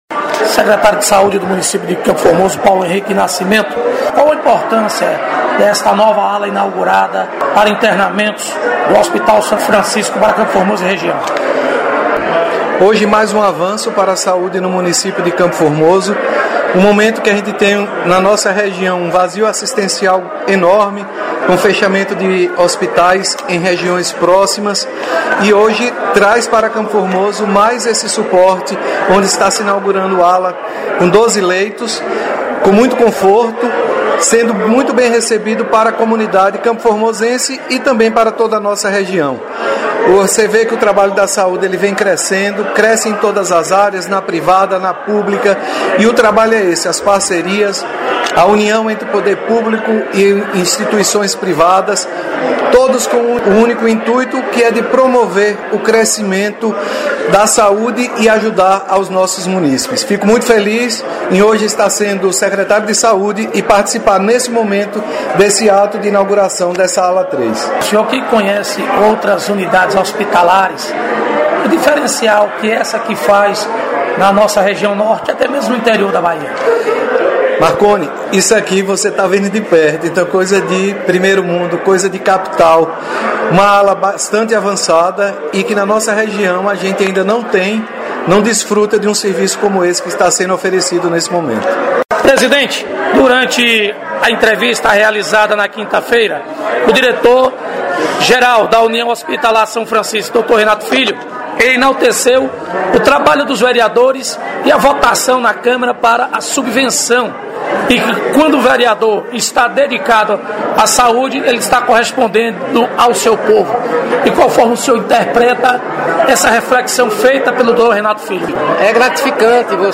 Reportagem: Inauguração de nova Ala do Hospital São Francisco de CFormoso